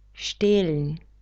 krast (640x564)krást stehlen [šte:ln]